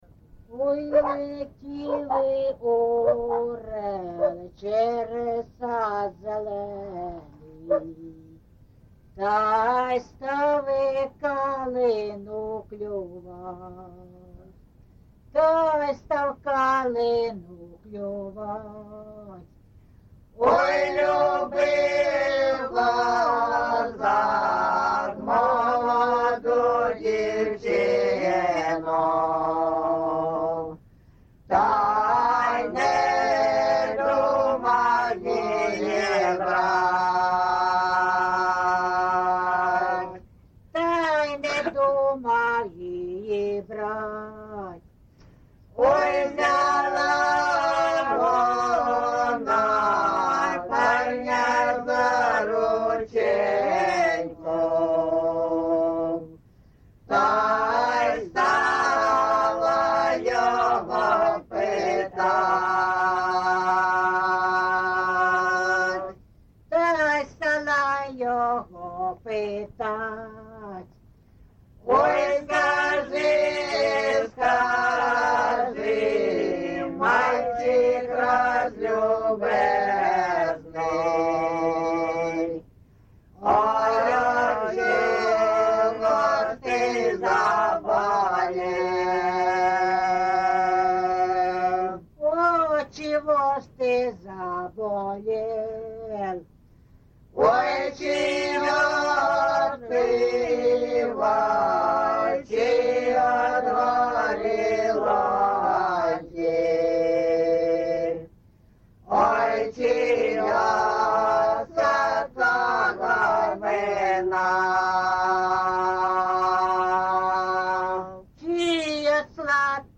ЖанрПісні з особистого та родинного життя, Балади